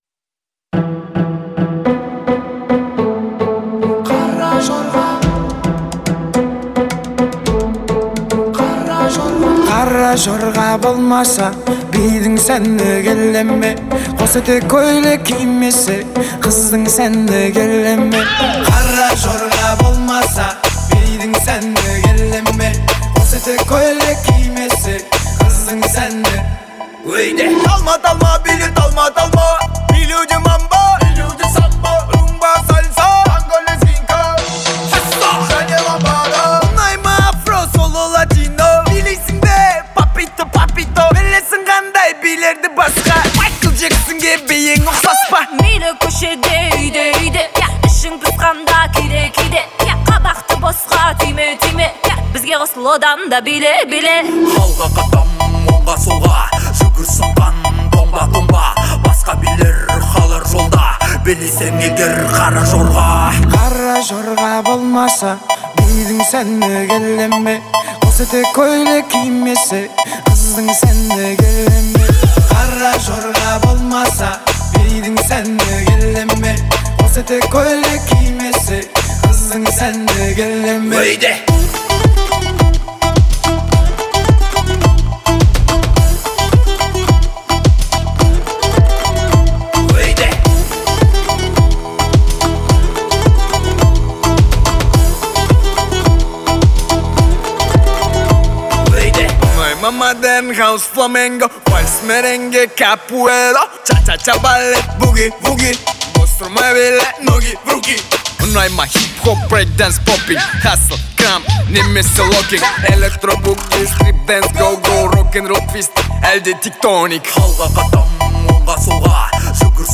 это яркая и энергичная композиция в жанре этно-поп